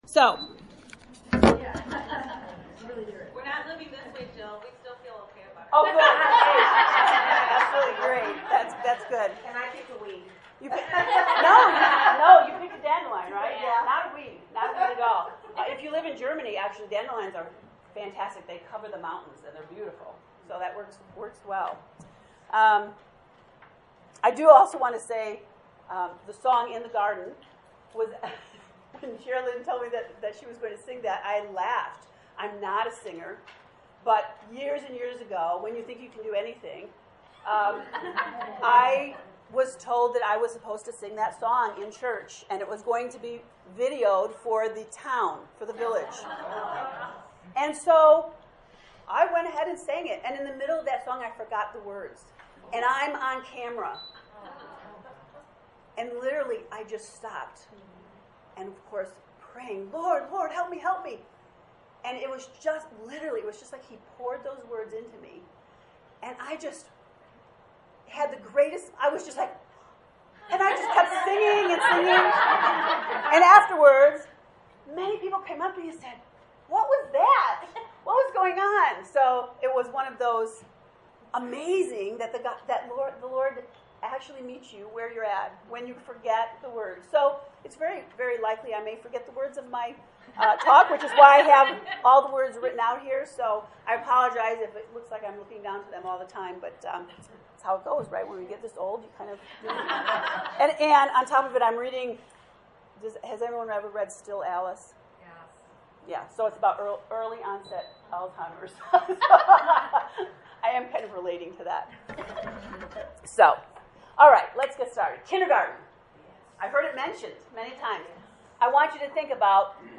This is a 2-part recording of the GARDEN RETREAT for the women of The Sanctuary Church in Bolingbrook, IL. The Garden Theme focused on the abundance of living our true identity which is discovered in the Garden of Eden.